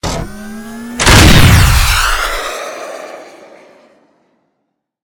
battlesuit_rocket.ogg